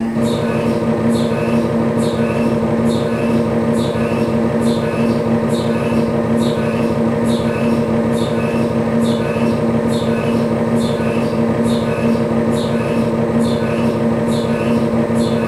MRI 1.3 Gauss Machine